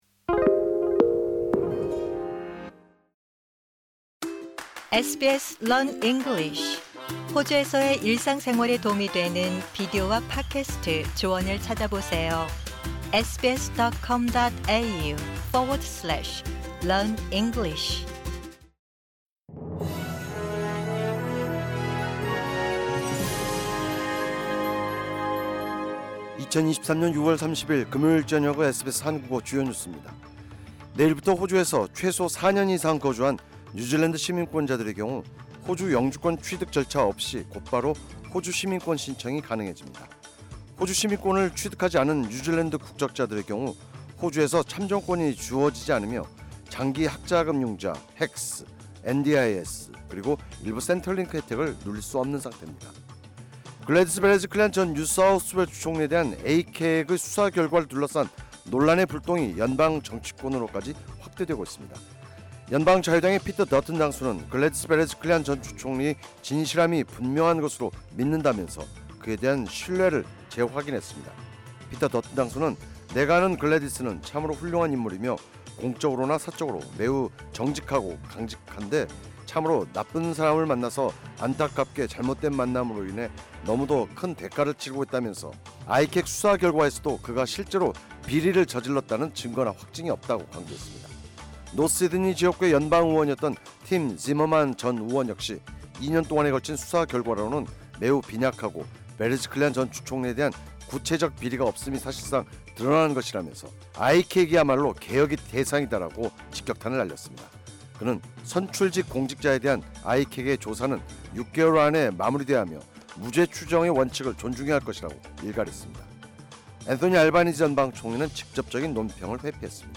2023년 6월 30일 금요일 저녁의 SBS 한국어 뉴스입니다.